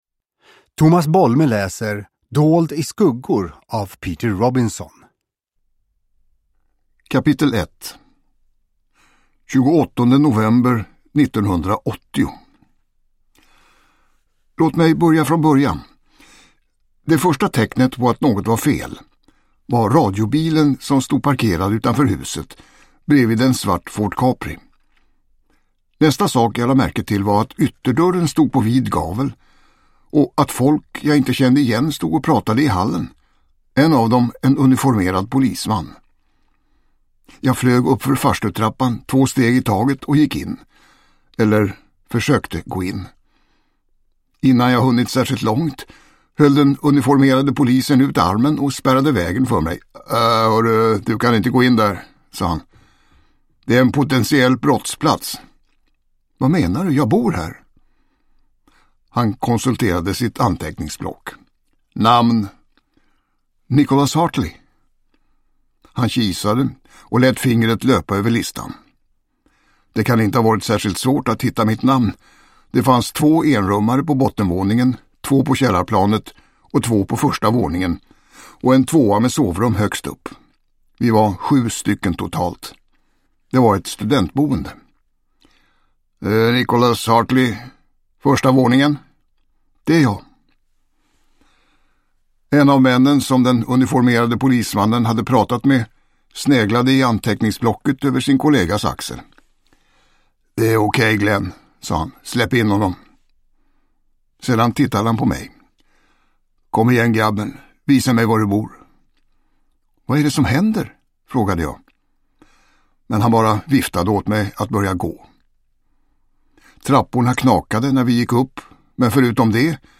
Dold i skuggor – Ljudbok – Laddas ner
Uppläsare: Tomas Bolme